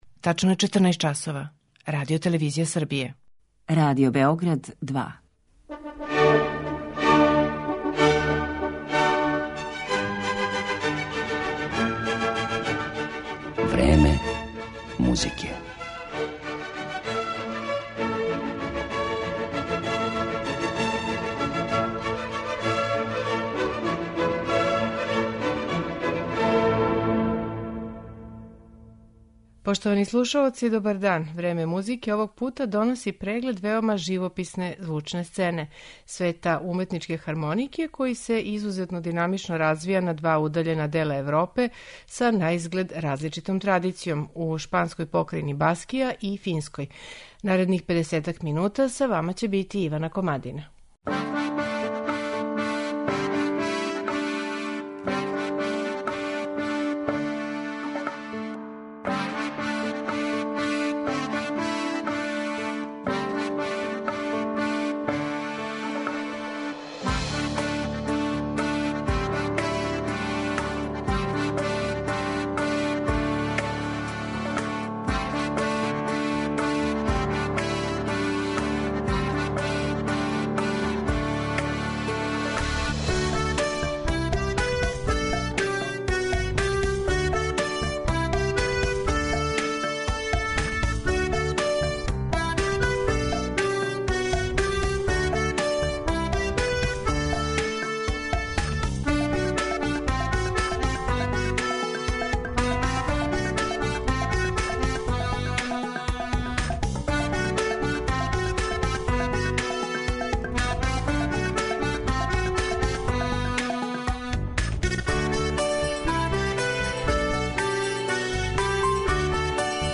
'Време музике' овог пута доноси преглед веома живописне звучне сцене - света уметничке хармонике који се изузетно динамично развија на два удаљена дела Европе, са наизглед различитом традицијом: у шпанској покрајини Баскији и Финској.